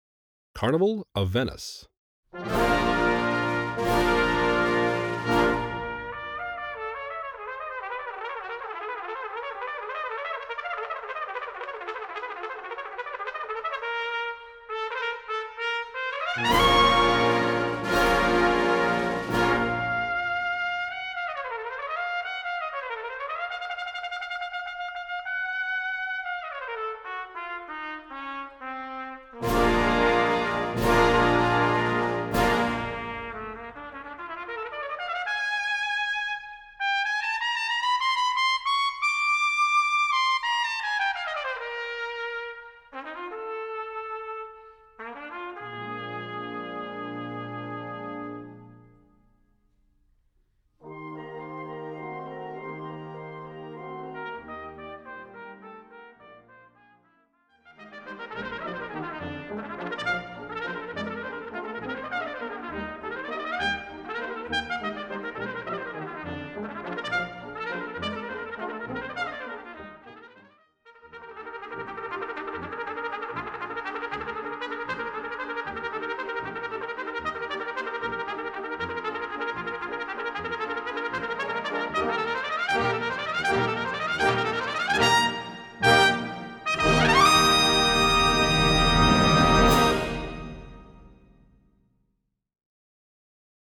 Voicing: Trumpet w/ Band